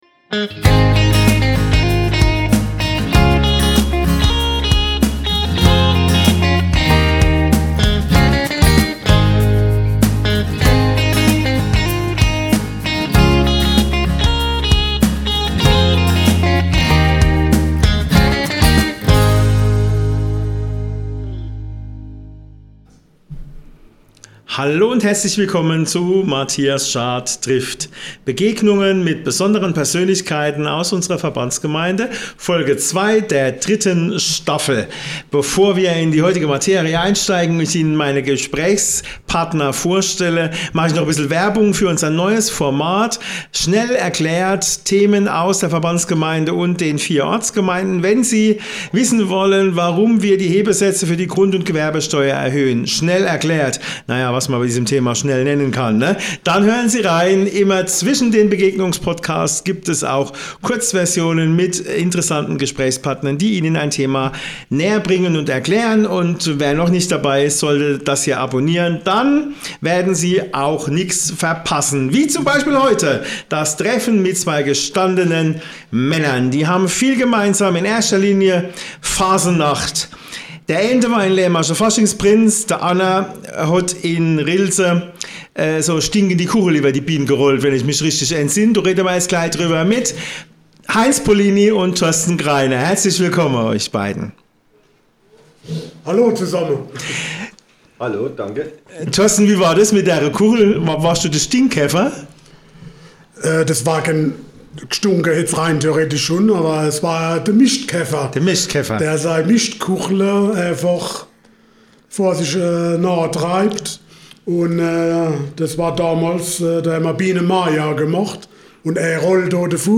Die drei sprechen über die Arbeit der beiden Vereine, die Herausforderung der Nachwuchsgewinnung, die Frage der sozialen Gerechtigkeit und darüber, ob es Möglichkeiten zur Zusammenarbeit gibt.